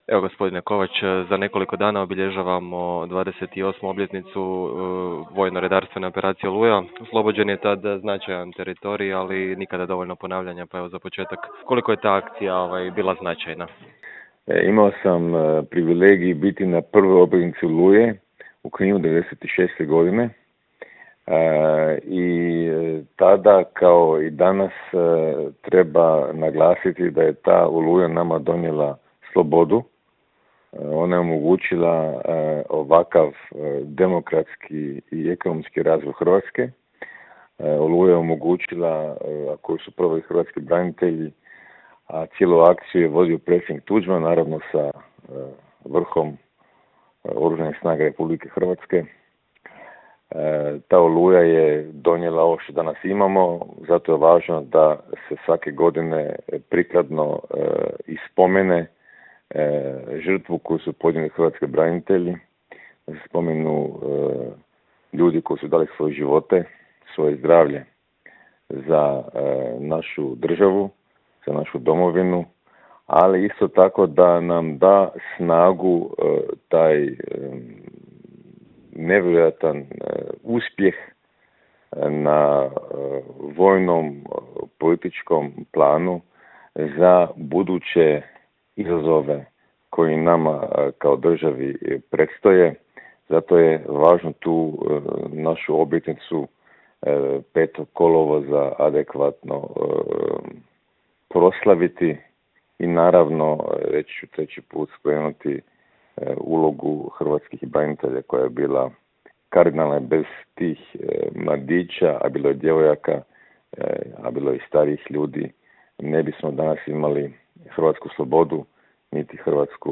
ZAGREB - Ususret novoj 28. obljetnici VRO Oluja razgovarali smo s bivšim ministrom vanjskih i europskih poslova Mirom Kovačem koji je za Media servis...